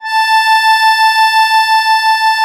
MUSETTESW.15.wav